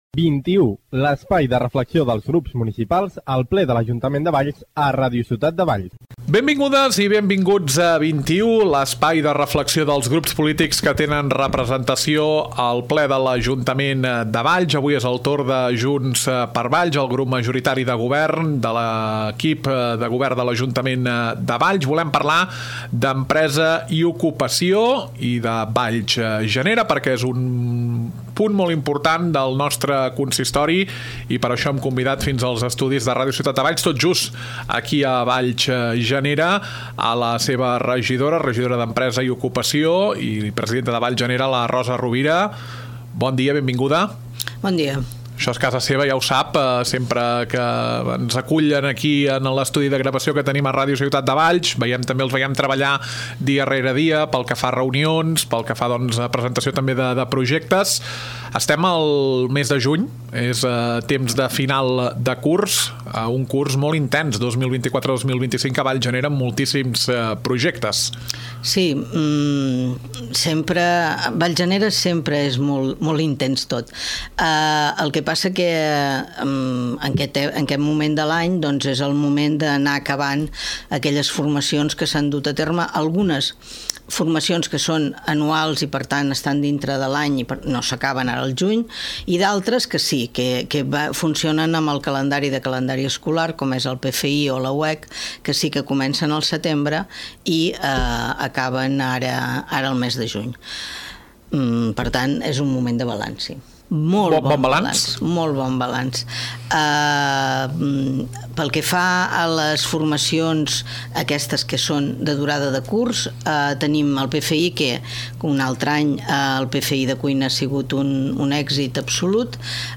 Entrevista a Rosa Rovira, regidora d’Empresa i Ocupació.